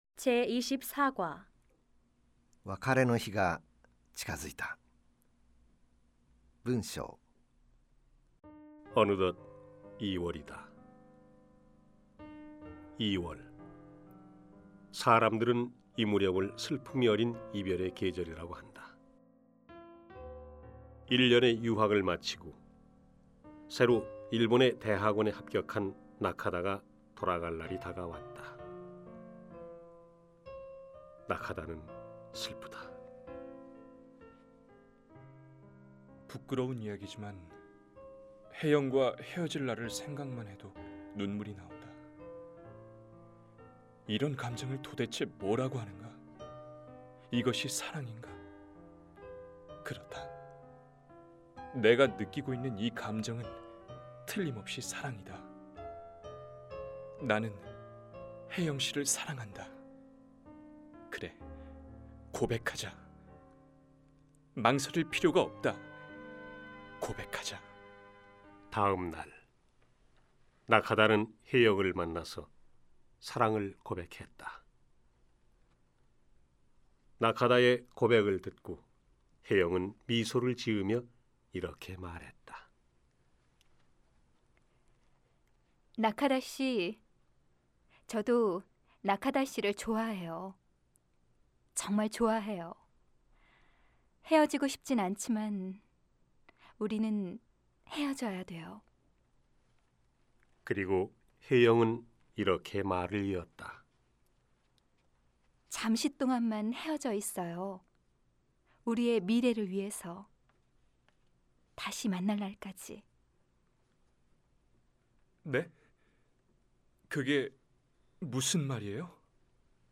CDは声優さんたちによるソウル現地録音を敢行．